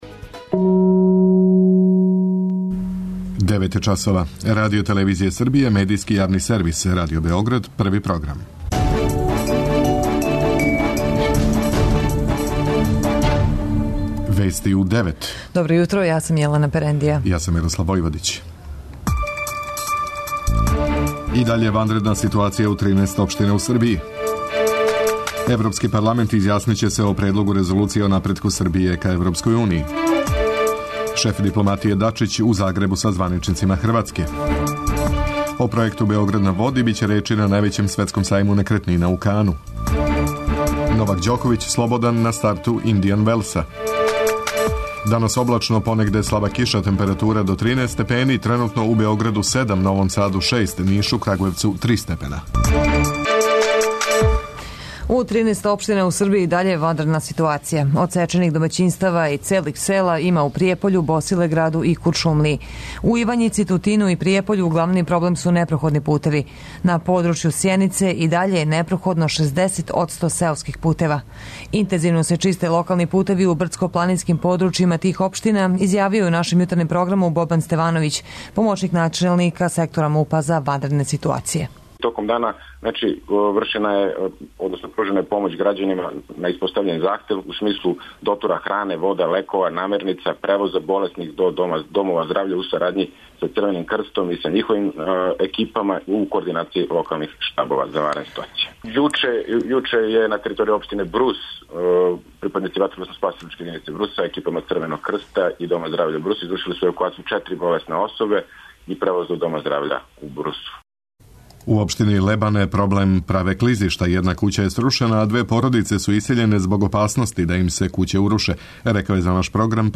преузми : 10.45 MB Вести у 9 Autor: разни аутори Преглед најважнијиx информација из земље из света.